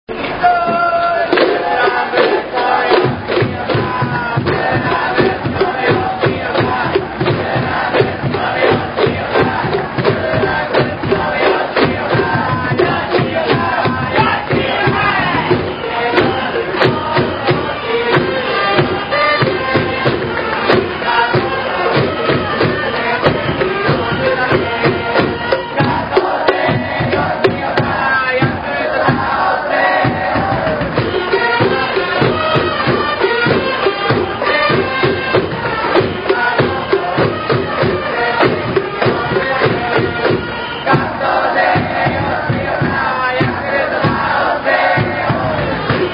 ２００３年応援歌